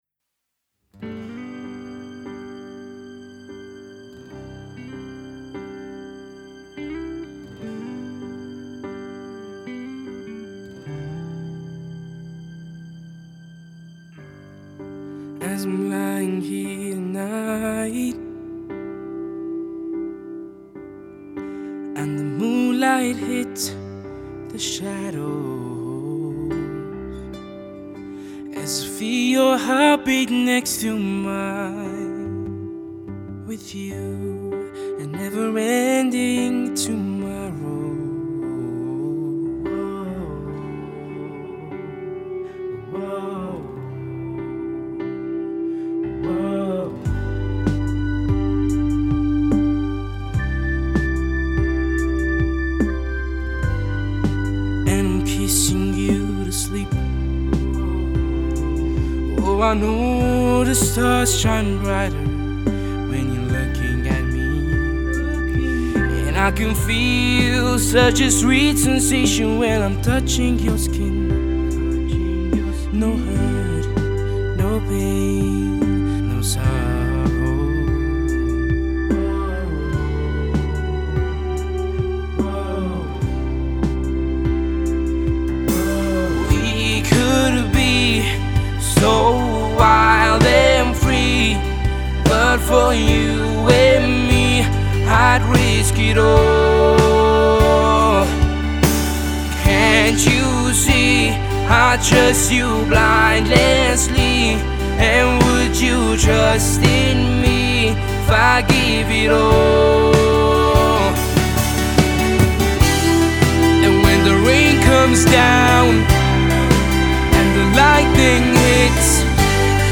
Slow Male